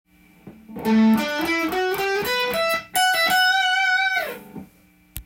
①のフレーズは、主にEマイナーペンタトニックスケール
を使用し９ｔｈと言われるテンションを強調したフレーズです。
このフレーズを弾かれると「ただ物ではない」雰囲気を出せます。